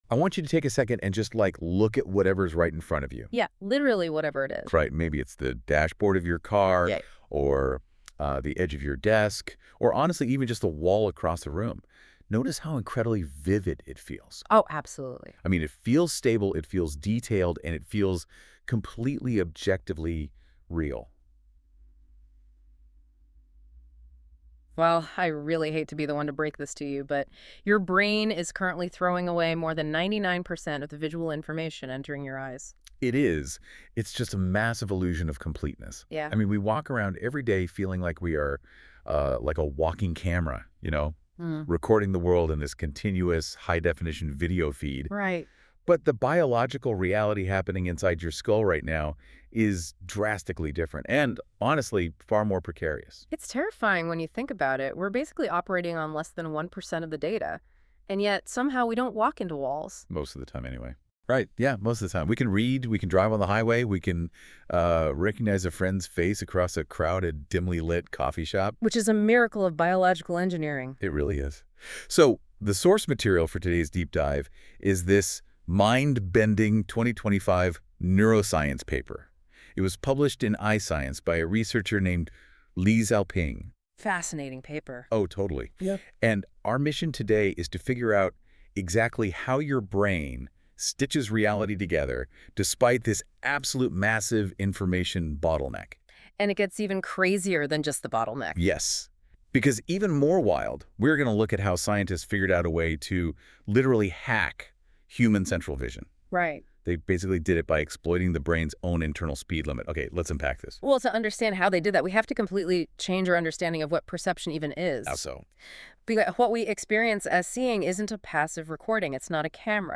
Click here for a podcast made via google AI.